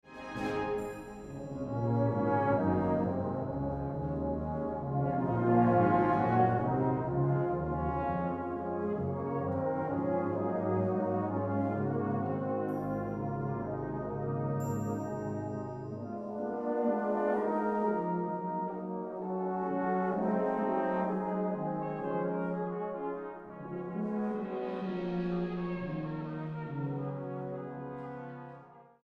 Australian singer songwriter
Style: Roots/Acoustic